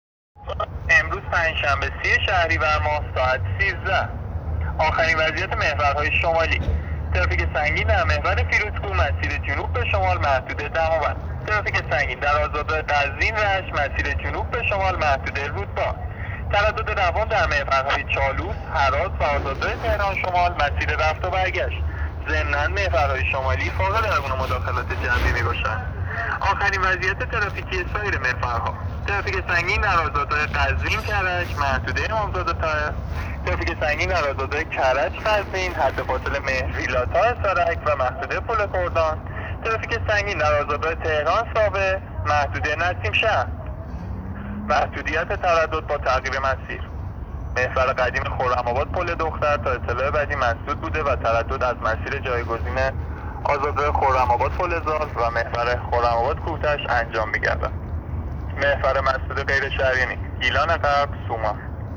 گزارش رادیو اینترنتی از آخرین وضعیت ترافیکی جاده‌ها تا ساعت ۱۳ سی‌ام شهریور؛